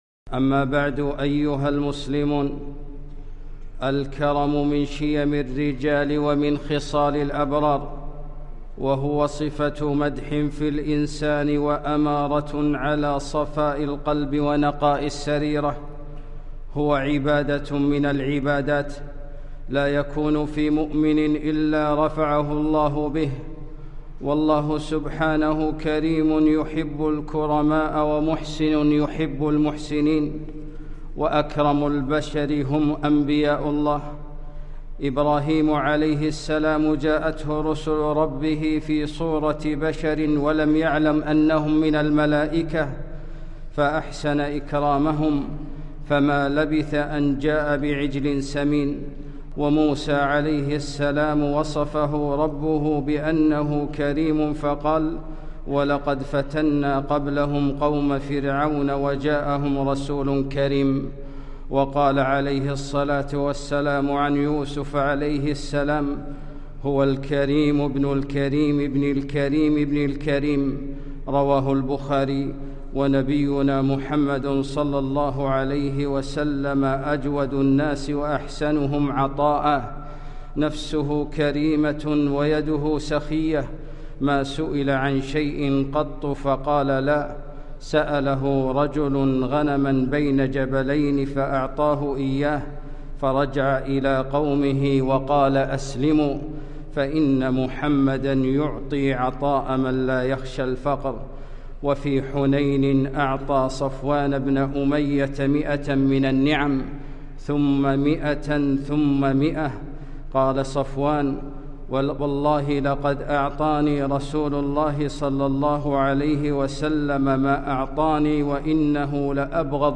خطبة - الـكـرم